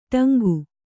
♪ ṭaŋgu